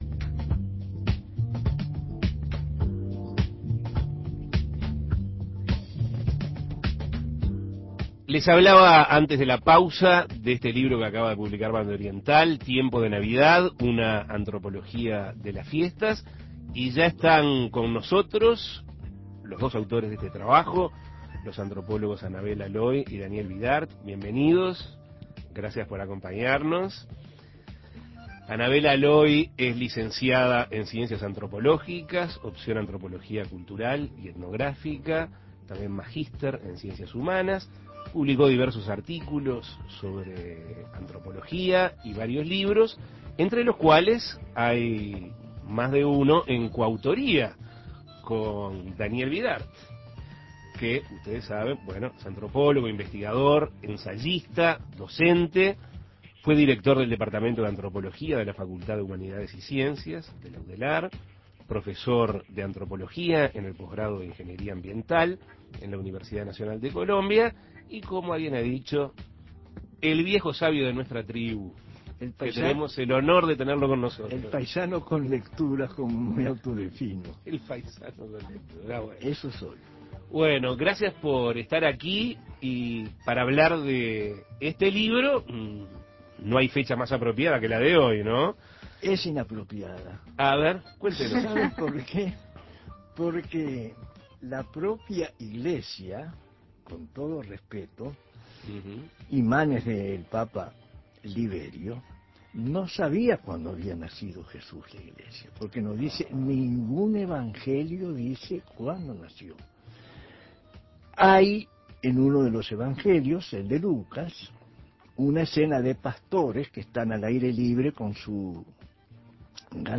El propósito de este trabajo es interpretar, desde el punto de vista antropológico, el origen, evolución y significado de las fiestas. Escuche la entrevista.